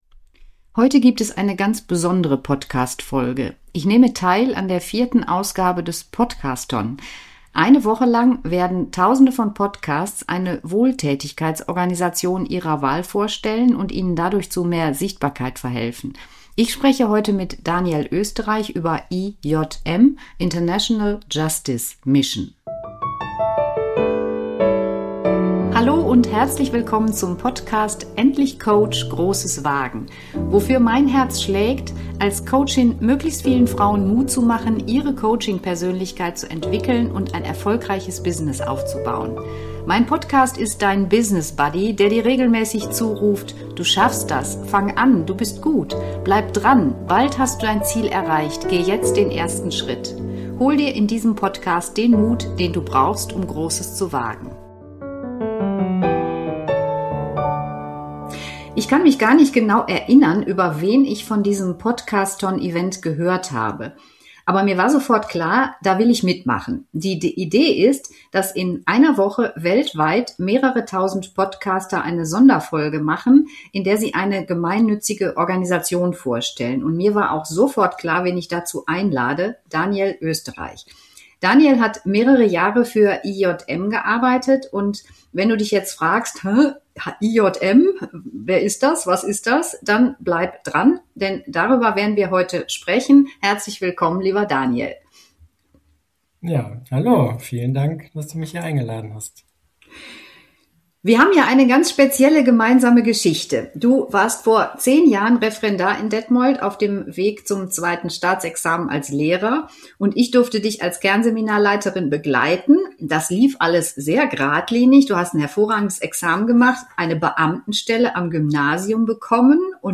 #075 IJM und die Arbeit gegen moderne Sklaverei. Im Gespräch